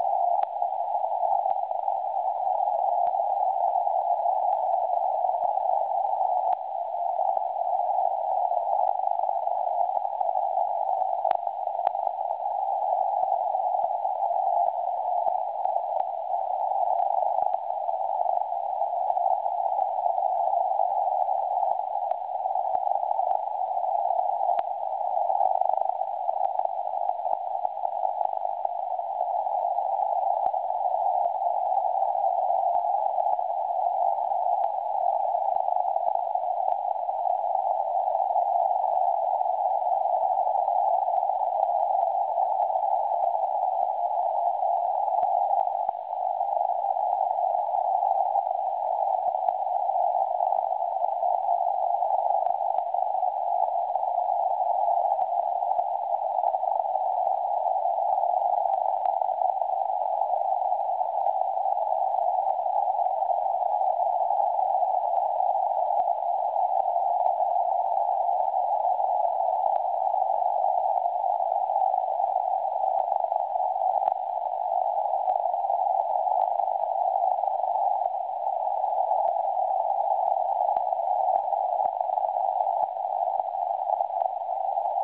Jen tak náhodou jsem si pustil SDR přijímač z Teplic (Hrad Doubravka).
Signál tam zřetelně je.
Maják (wav)
Maják je na 3580,23 KHz, ale na SDR musíte nastavit mod narrow CW (bylo nastaveno BW 150 Hz) a kmitočet 3580,08 KHz (kluci to mají ujeté).